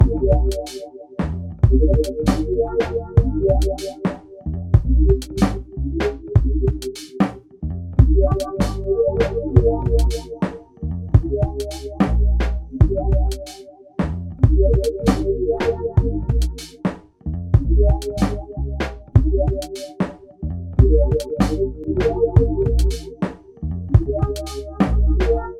A Boombap lofi vibe sample pack with a jazzy dark ambient aura that make this collection of samples perfect to improvise over and get deep, complex and introspective tracks